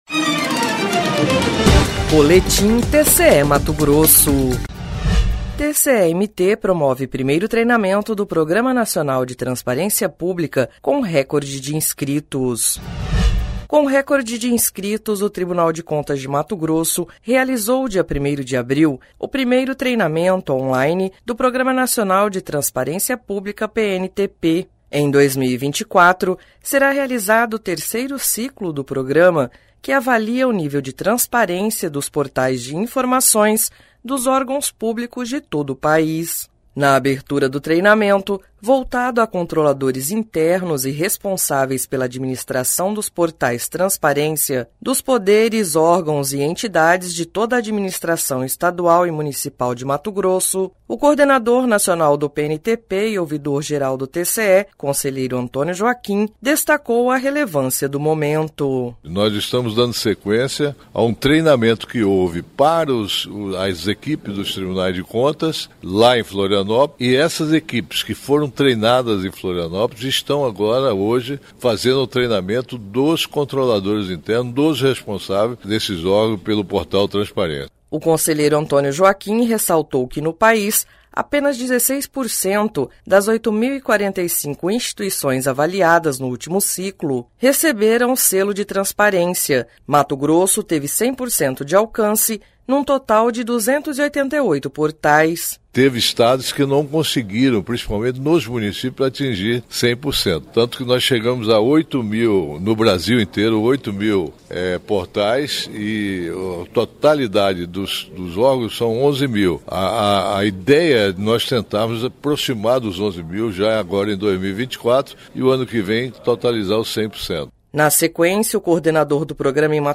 Sonora: Antonio Joaquim – conselheiro coordenador nacional do PNTP e ouvidor-geral do TCE-MT